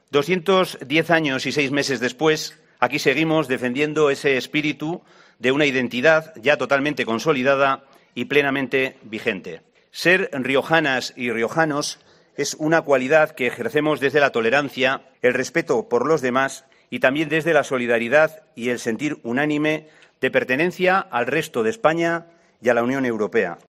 La localidad ha acogido el acto institucional del Pregón del Día de La Rioja.
García ha pronunciado estas palabras en su intervención en el acto institucional del pregón del Día de La Rioja, que como cada 8 de junio se celebra en la localidad de Santa Coloma, en recuerdo de la reunión de alcaldes riojanos que se celebró en este lugar, en diciembre de 1812, para reclamar mayor autonomía para esta tierra.